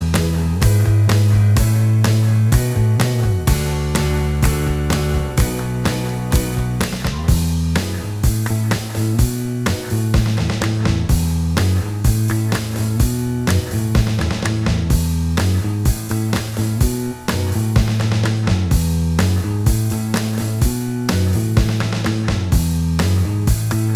Minus Guitars Rock 3:12 Buy £1.50